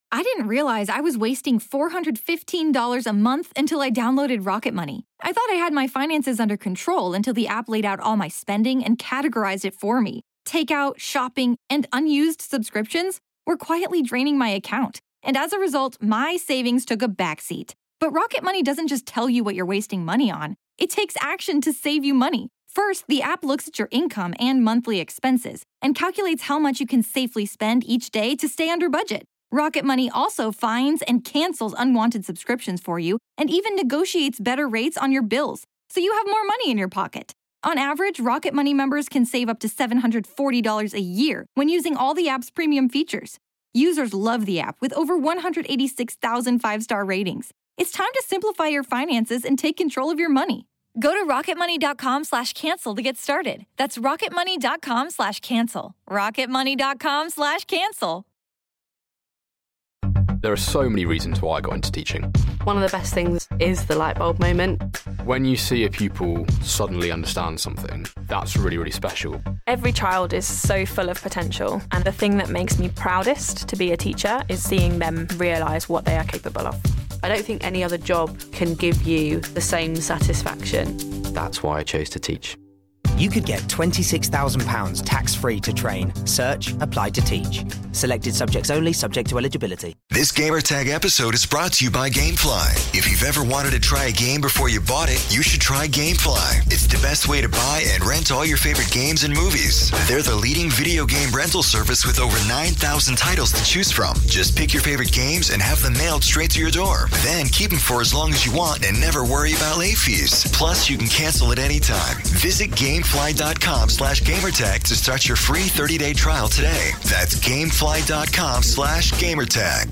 E3 2016 day 3 roundtable discussion